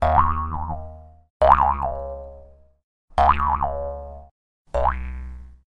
bounce.ogg